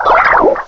cry_not_solosis.aif